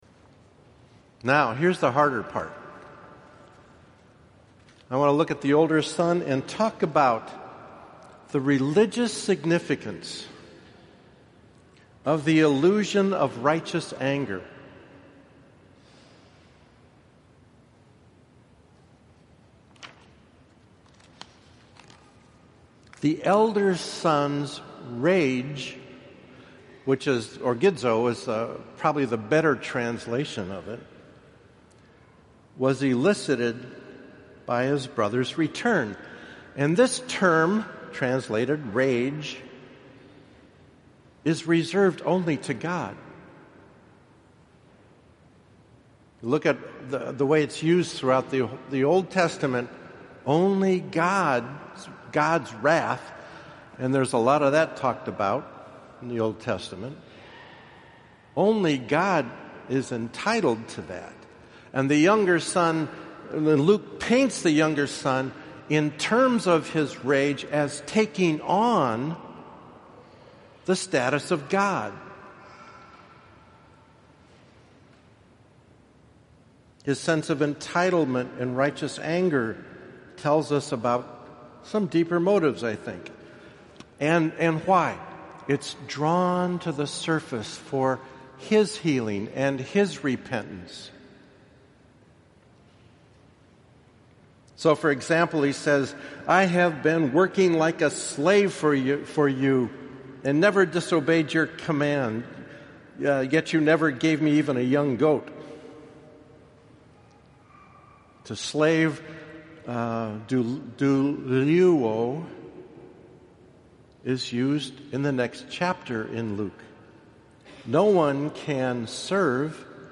POSTS: Video Commentaries & Homilies (Audio)